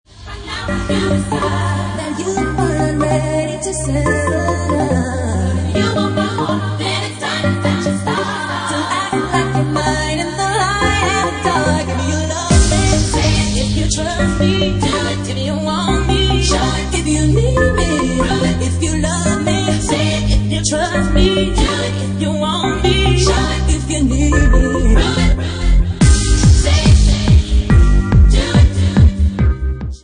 Genre:Bassline House
Bassline House at 147 bpm